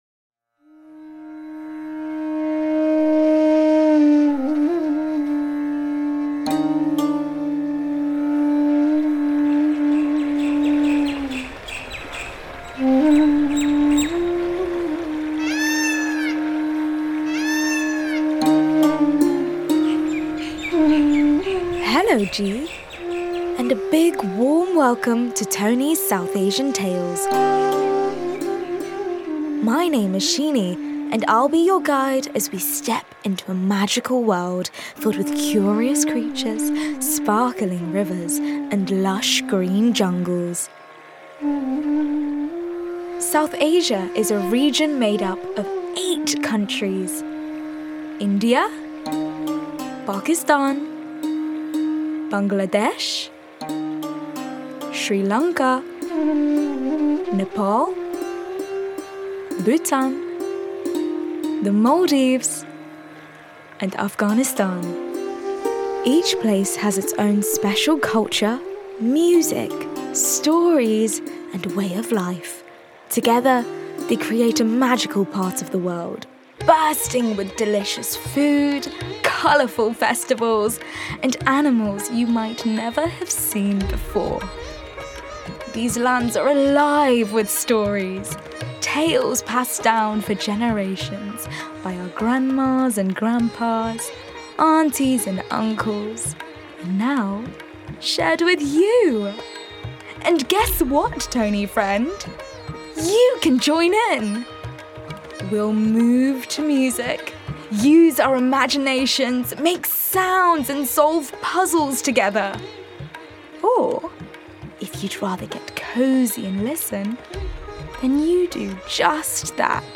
South Asian Tales - A captivating storytelling experience coming soon.
The folklore, myths and legends of South Asia are rich with curious beasts, colours, sights and smells. TikTok sensation and Blue Peter presenter Shini Muthukrishnan takes listeners on a journey that will propel Tonie kids into a world of magical palaces, jungles and mangroves through lush soundscapes and musical adventure.